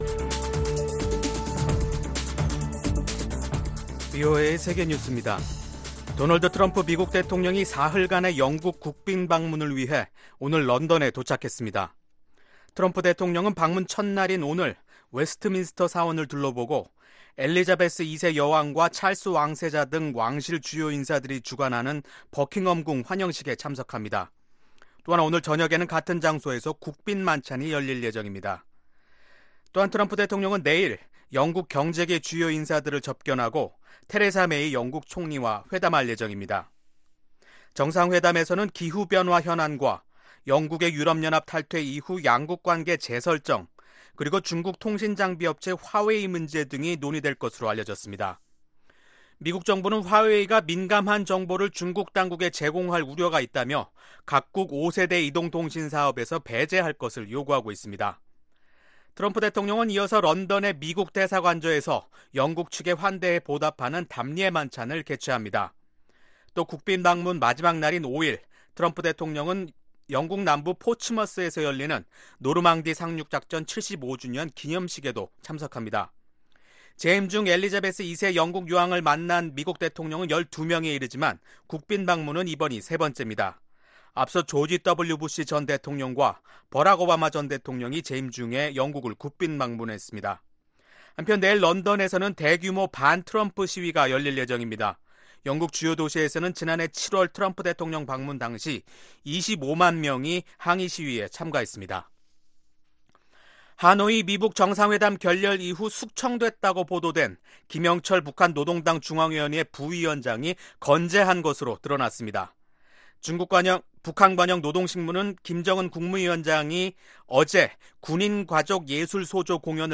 VOA 한국어 간판 뉴스 프로그램 '뉴스 투데이', 2019년 6월 3일 2부 방송입니다. 한국을 방문한 패트릭 섀너핸 미 국방장관 대행은, 완전하고 검증된 한반도 비핵화가 수용할 수 있는 유일한 최종 상태라고 말했습니다. 한국 정부는 북한에서 발생한 아프리카돼지열병이 유입되는 것을 막기 위해 최고 수준의 방역 태세를 갖추기로 했습니다.